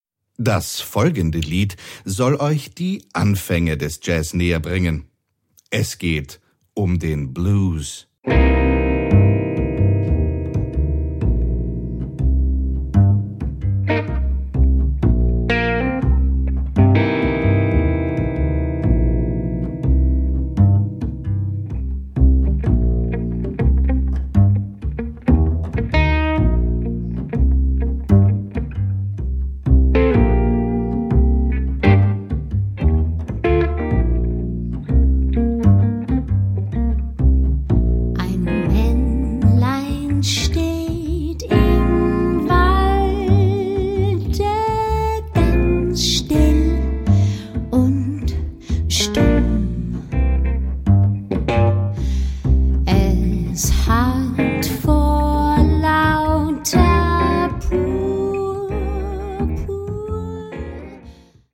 Jazz für Kinder: Blues, Swing, Latin & Co.